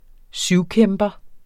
Udtale [ ˈsyw- ]